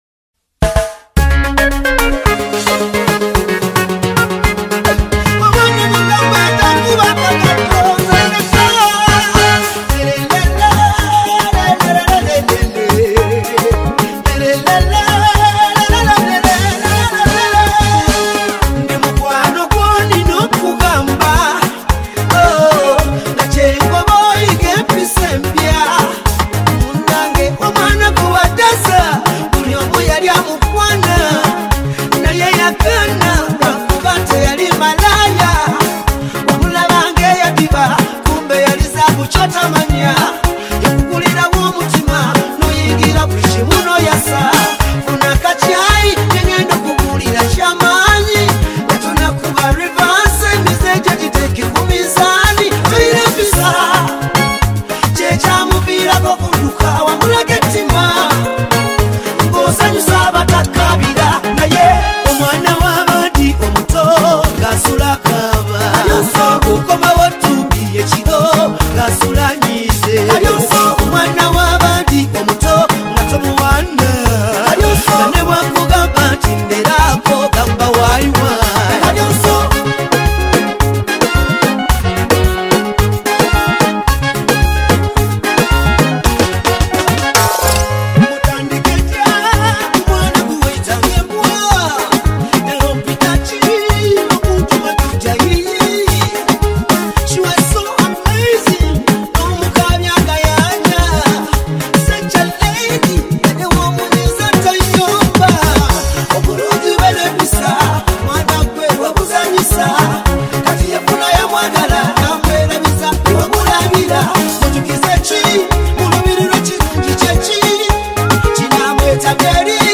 strong vocals